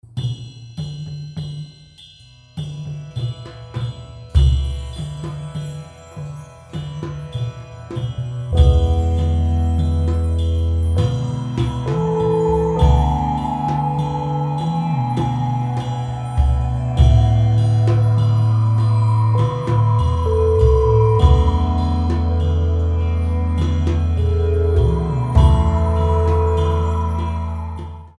seven beats